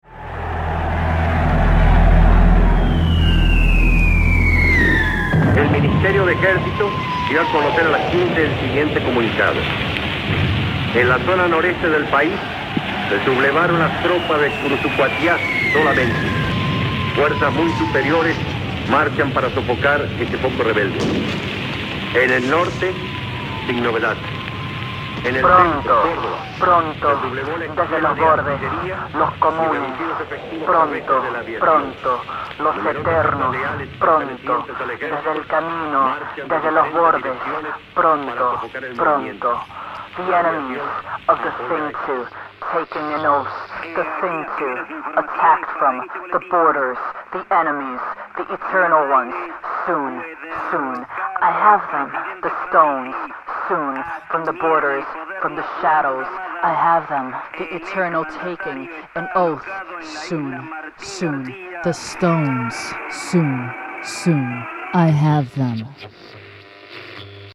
Soundtrack.
Dixon Place, New York City